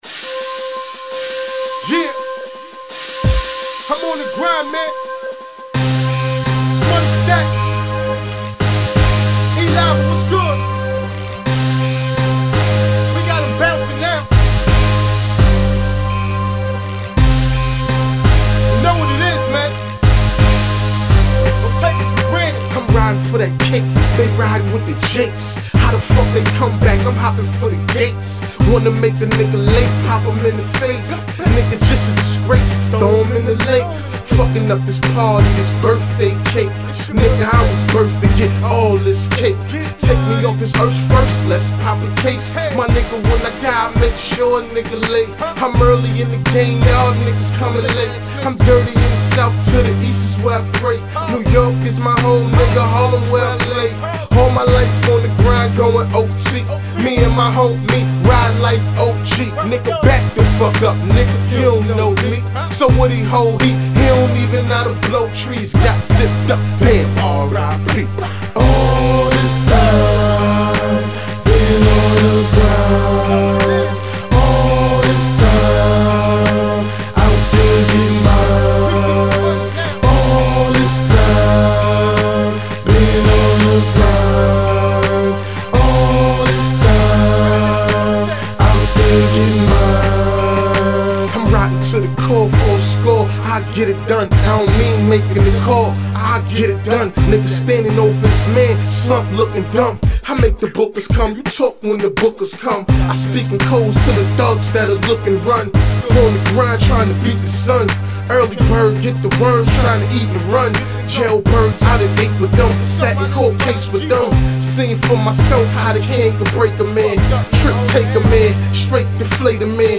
Striking, cinematic tones kick off this hustler’s anthem.
Known for his confident delivery and unapologetic presence